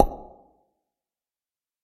Звук лопнувшего мыльного пузыря